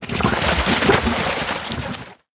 orge_vomit_03_vomit.ogg